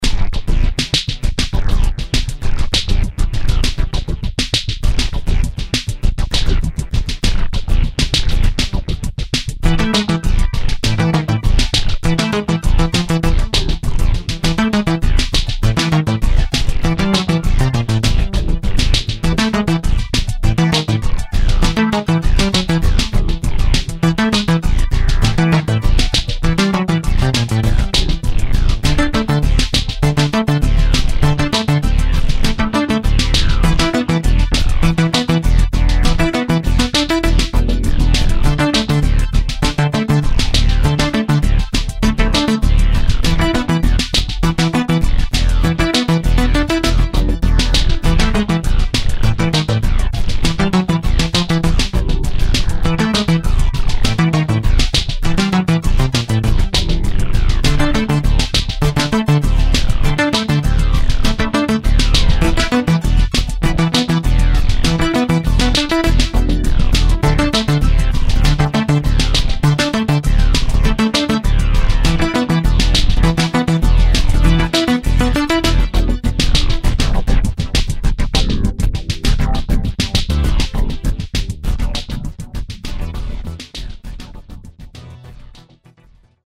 Korg TRITON Extreme / samples / combinations
заводская комбинация B031 - "Chord Hold"